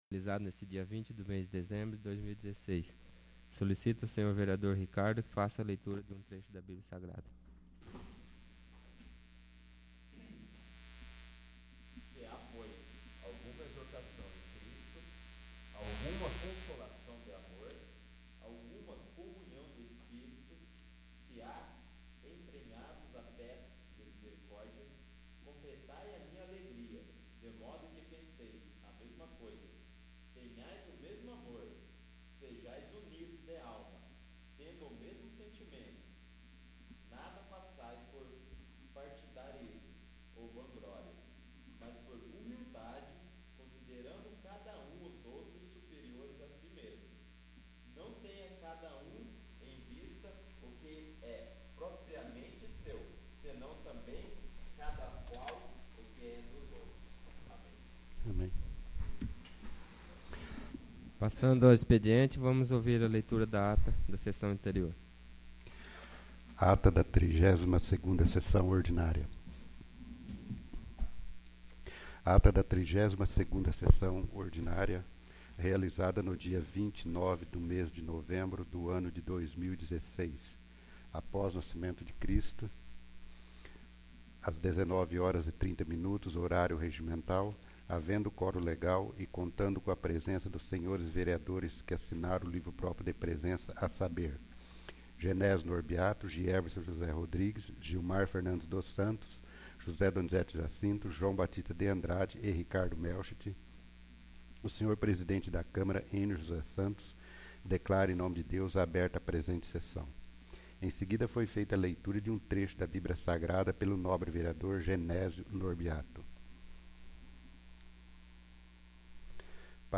33º. Sessão Ordinária